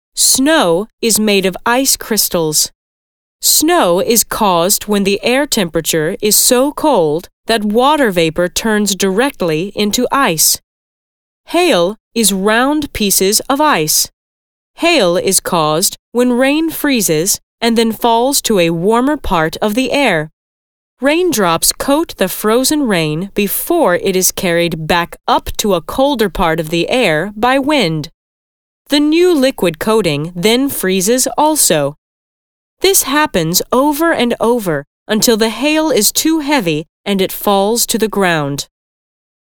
Audiobooks and E-learning